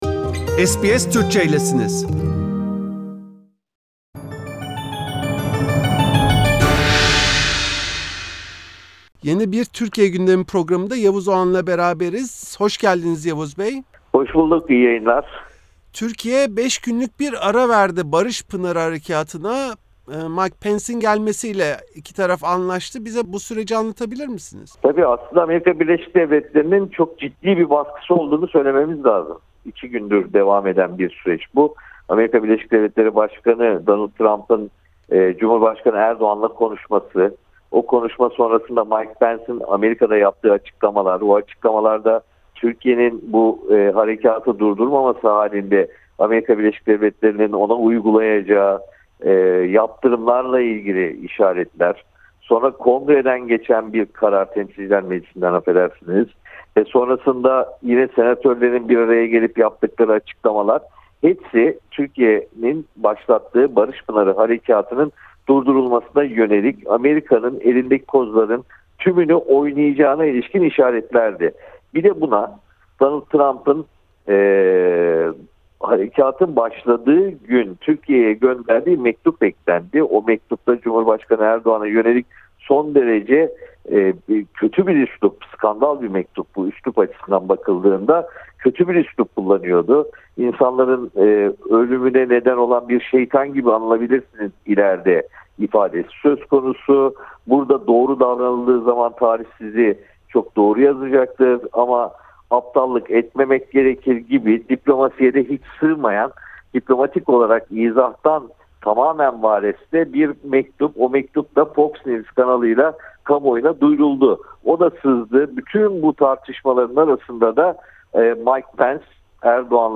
Gazeteci Yavuz Oğhan, SBS Türkçe’ye verdiği röportajda, Barış Pınarı operasyonuna verilen 5 günlük arayı değerlendirdi. Ayrıca, Rusya’nın tüm bu süreç içinde oynadığı kilit role değindi.